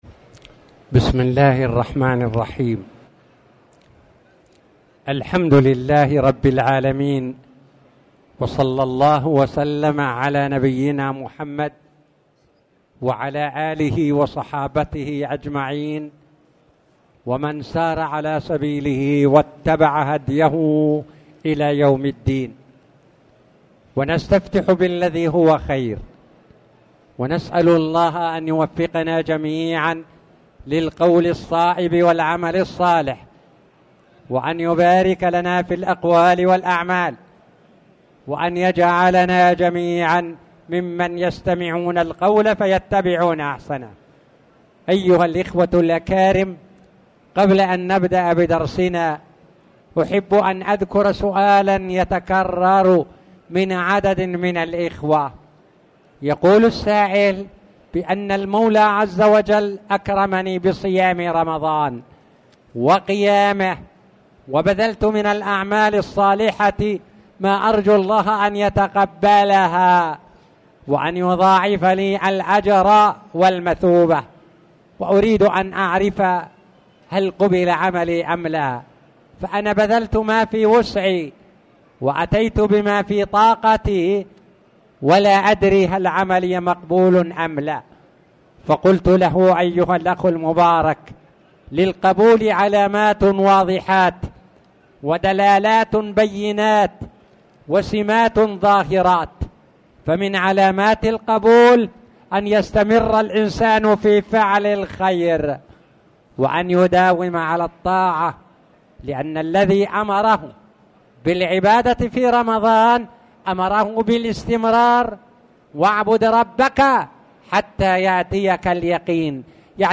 تاريخ النشر ١٥ شوال ١٤٣٨ هـ المكان: المسجد الحرام الشيخ